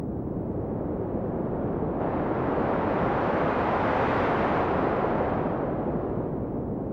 wind.mp3